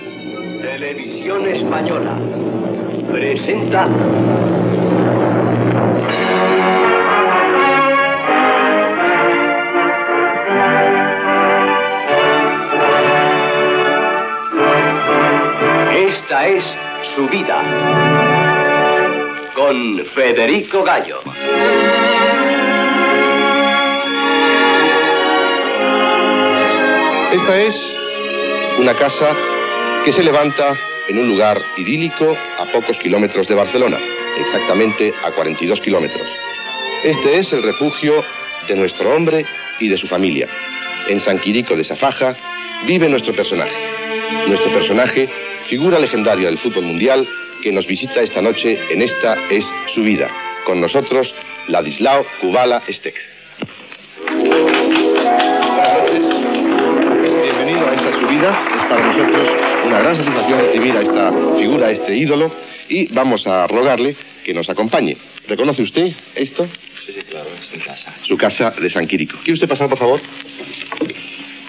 Fragment d'un dels programes de televisió
Divulgació
Programa presentat per Joan Manuel Serrat